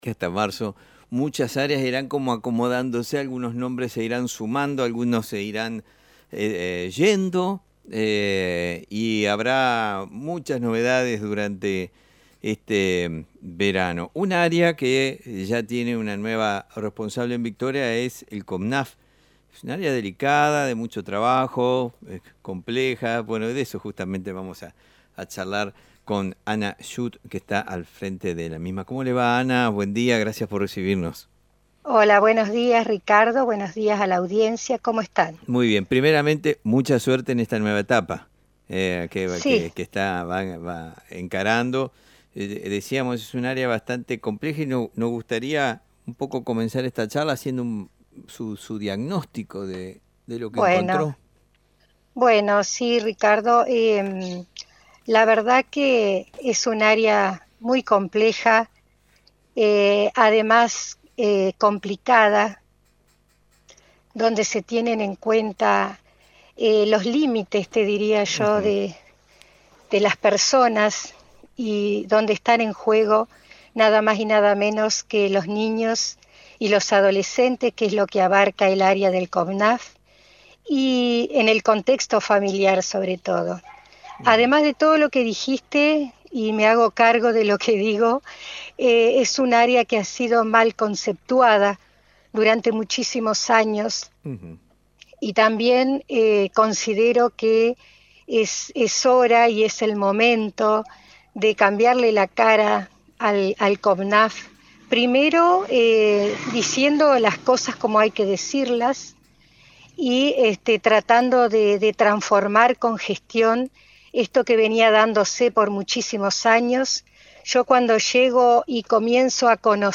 En una reciente entrevista por AM 980, Ana Schuth compartió sus impresiones sobre la complejidad y delicadeza del trabajo en el COPNAF.